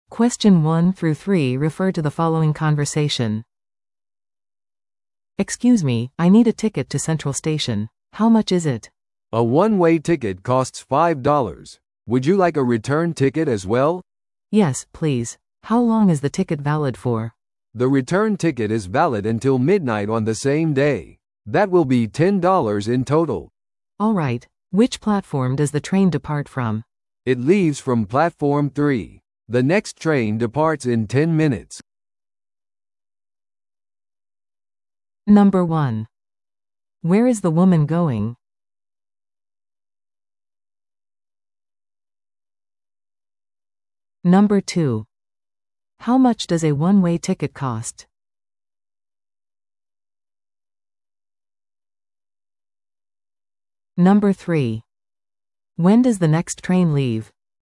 No.1. Where is the woman going?